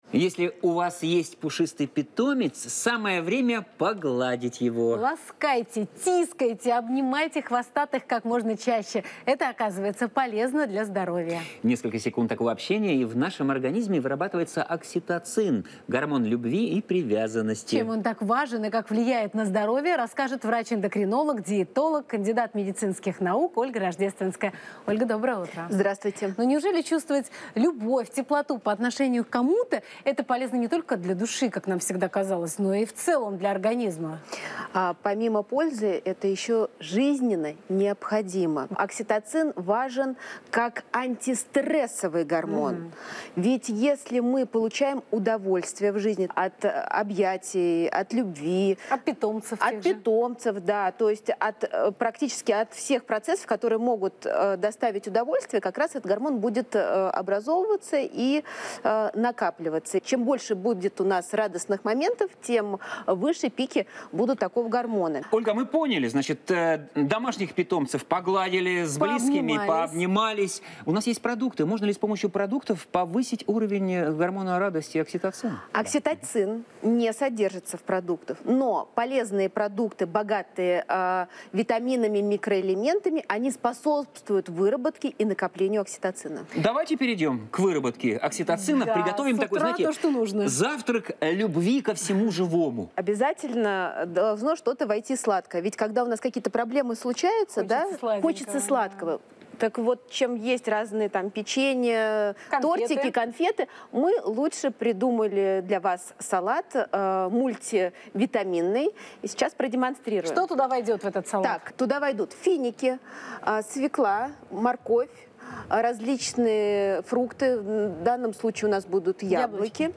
Источник: телеканал ТВЦ